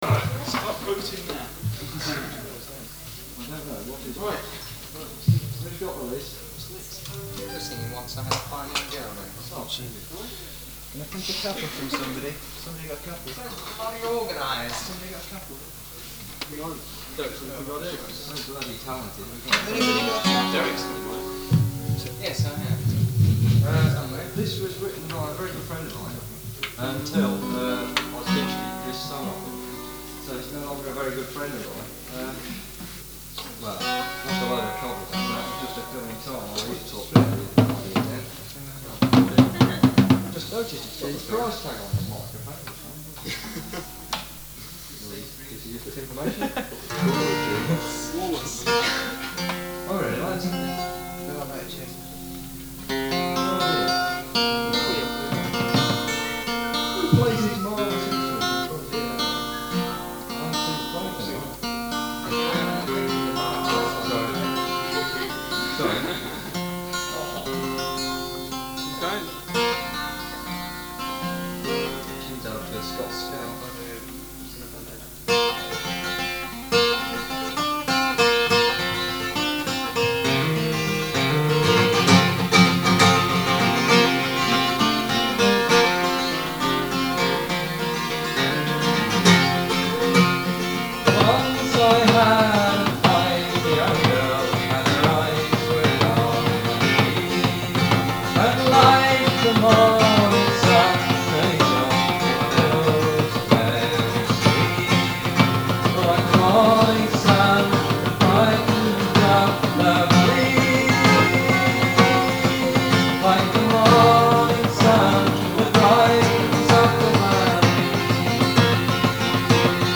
Guitars, Bass, Percussion, Vocals
Mandolin, Bouzouki, viola, Vocals
Fiddle, Vocals